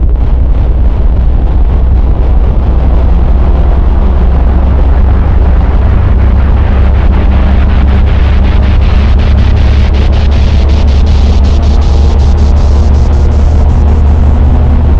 Starship Take Off
The sound of a starship revving up getting ready to launch.
starship_take_off_0.mp3